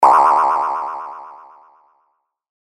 Sound Effects Library. Comedy Boing Sound (CC BY-SA)
sound-effects-library-comedy-boing-sound.mp3